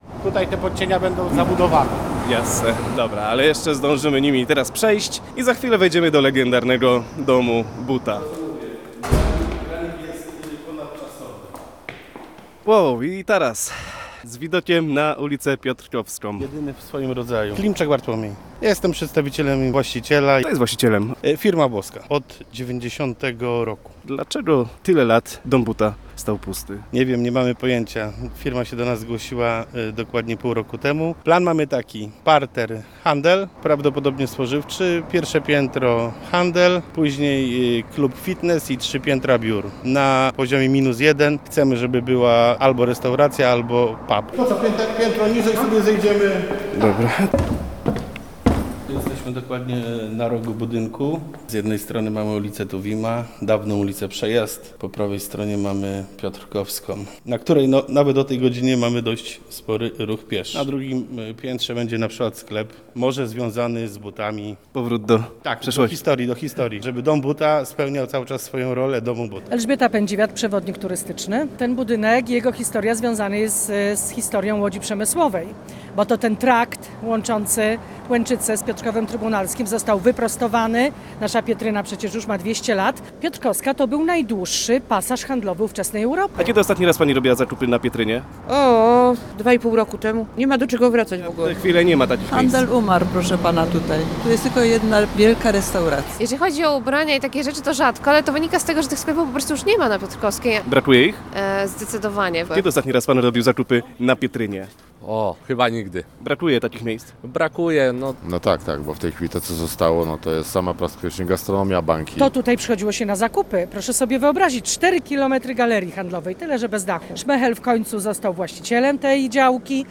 Rozmowa z przewodnikiem turystycznym